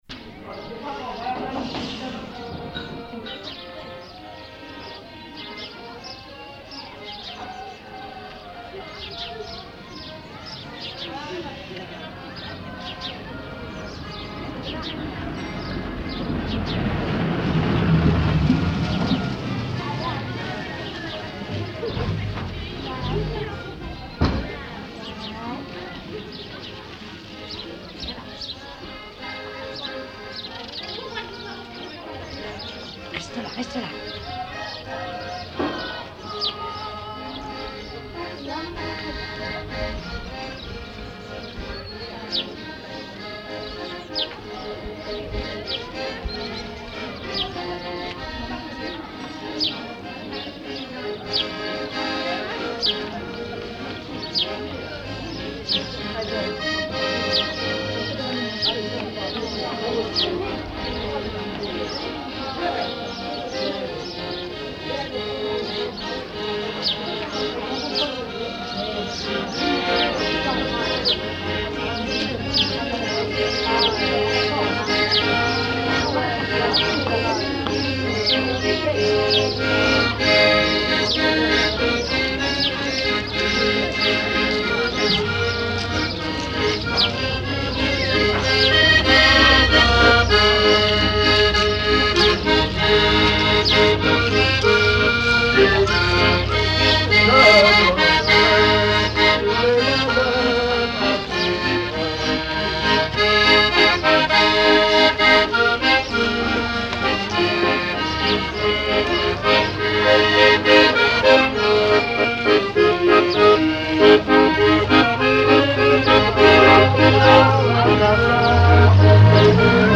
Mémoires et Patrimoines vivants - RaddO est une base de données d'archives iconographiques et sonores.
Cortège à la sortie de l'église
Sons extérieurs du cortège de noce
Pièce musicale inédite